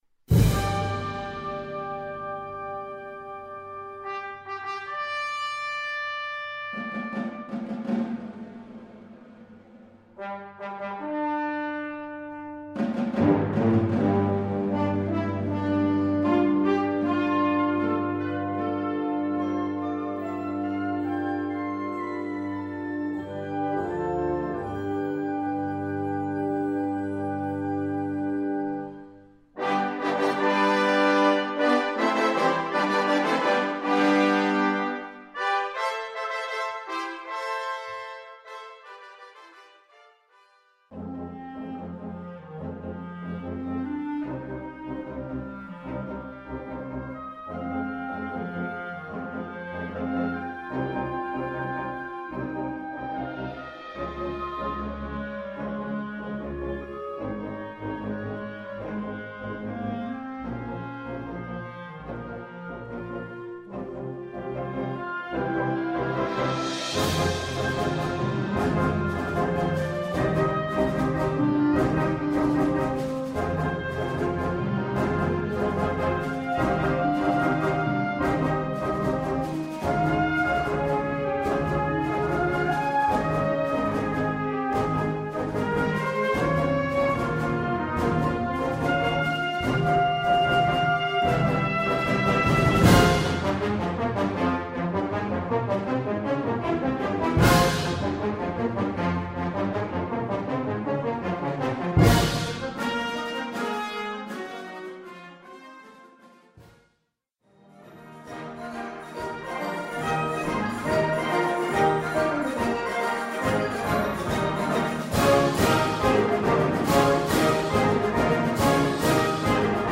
Categorie Harmonie/Fanfare/Brass-orkest
Subcategorie Concertmuziek
Bezetting Ha (harmonieorkest)
klinkt als de muziek bij een oude Ierse sage.